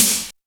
BIG SD 2.wav